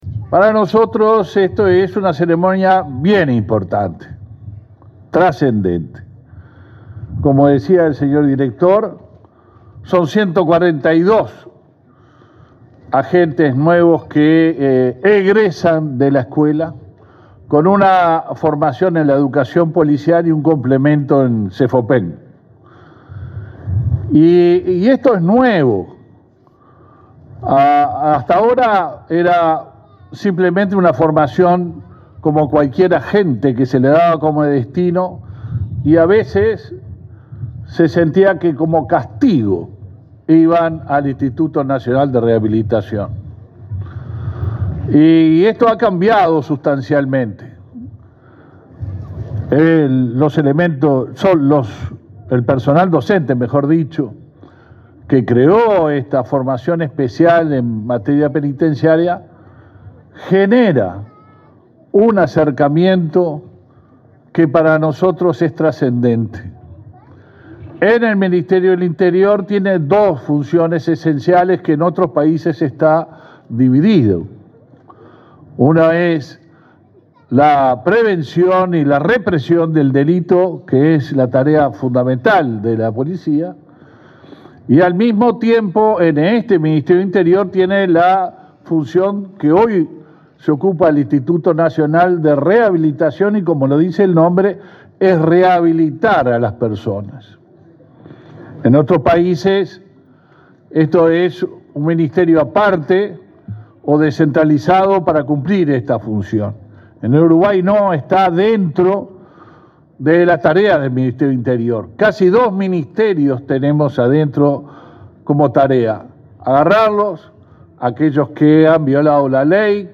Palabras del ministro del Interior, Luis Alberto Heber
Este martes 9, en Montevideo, el ministro del Interior, Luis Alberto Heber, participó en la ceremonia de egreso de agentes penitenciarios.